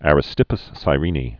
(ărĭ-stĭpəs; sī-rēnē) 435?-366?